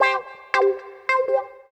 137 GTR 10-R.wav